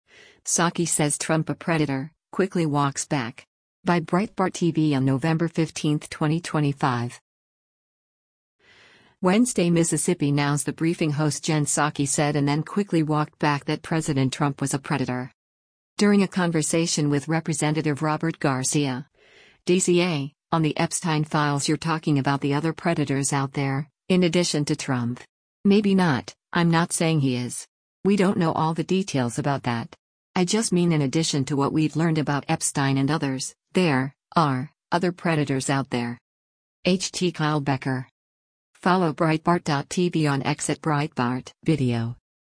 Wednesday, MS NOW’s “The Briefing” host Jen Psaki said and then quickly walked back that President Trump was a predator.